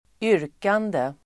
Uttal: [²'yr:kande]